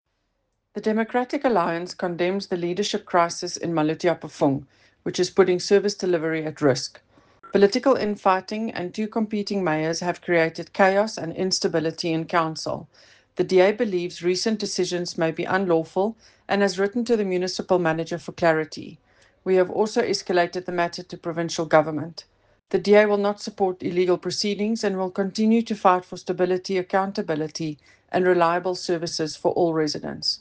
Afrikaans soundbites by Cllr Eleanor Quinta and